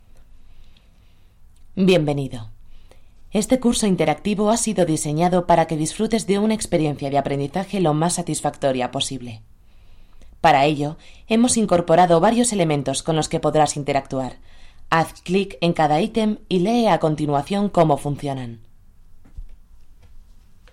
Voz de un rango entre los 15 años y los 55. Posibilidad de hacer niños y niñas pequeños. Puedo hacer desde un anuncio con voz sexy y cálida hasta algo muy rápido y completamente cantado. Puedo poner voz clásica de locutora o salir de esos registros y anunciarte todo con un tono de calle, mas neutral. Realizo también presentaciones y todo lo que se te ocurra! _____________________________________________________________ Voice of a range between 15 years and 55. Possibility of making small children. I can do from an ad with a sexy and warm voice to something very fast and completely sung. I can put the classic voice of announcer or leave those records and announce everything with a tone of street, more neutral. I also make presentations and everything you can think of!
Sprechprobe: eLearning (Muttersprache):